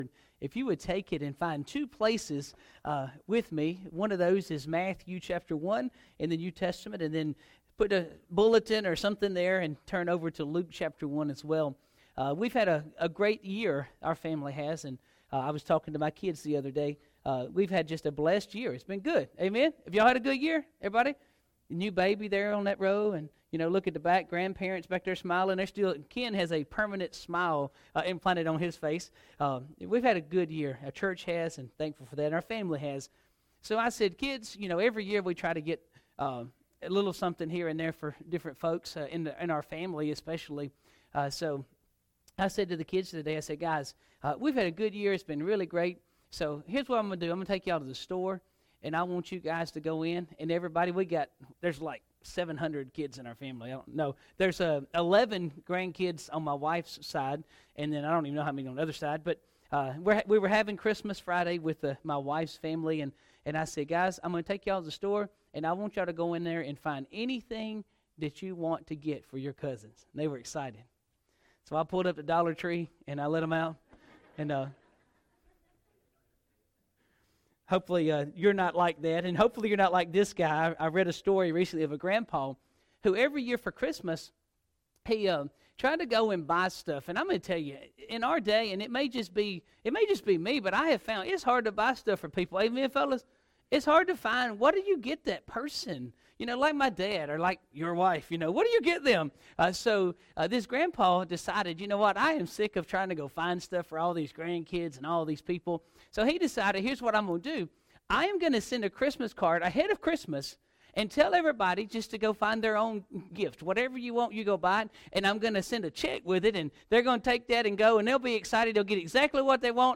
Sermons | Shady Grove Church